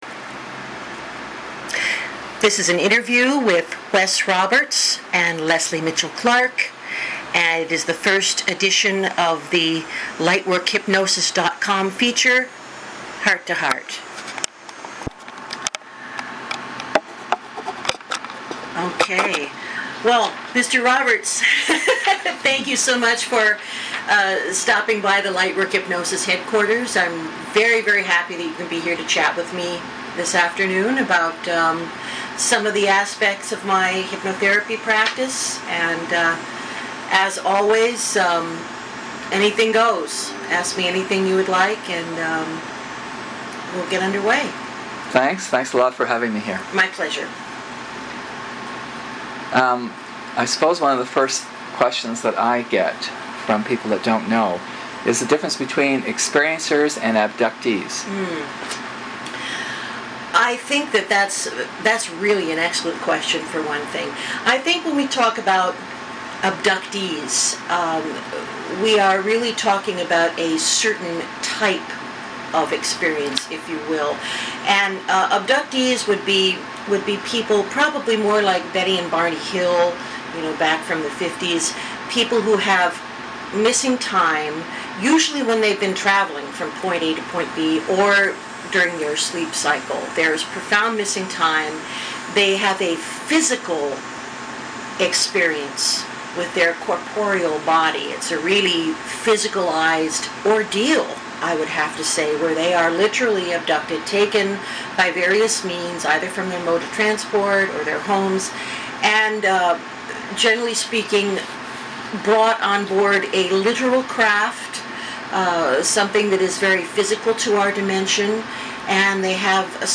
LIGHTWORK HYPNOSIS is thrilled to announce a new interview feature, "Other Voices".